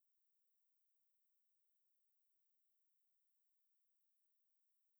Attached are two files, one that contains “rounding noise” produced by amplifying a 440 Hz sine wave by 1.5 dB. The other contains shaped dither from the same process with the same 440 Hz tone. The original 440 Hz tone has been removed from each.